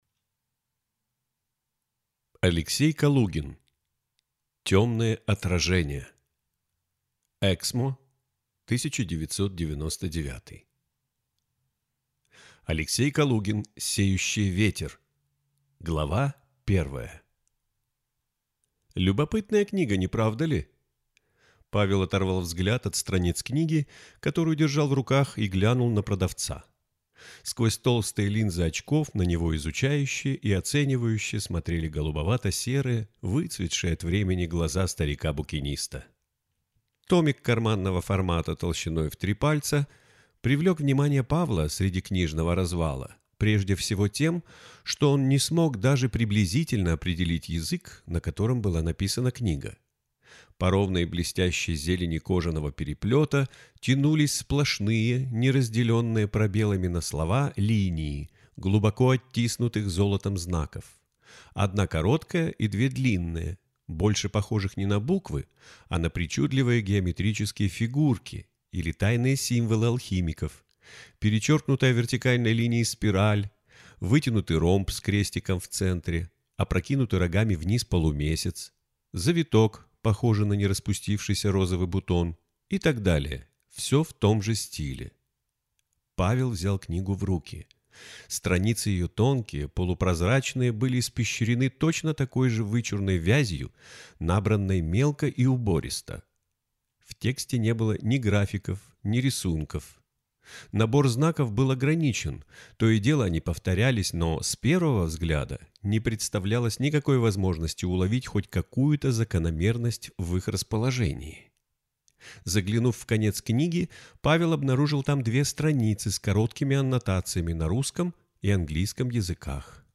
Аудиокнига Тёмные отражения | Библиотека аудиокниг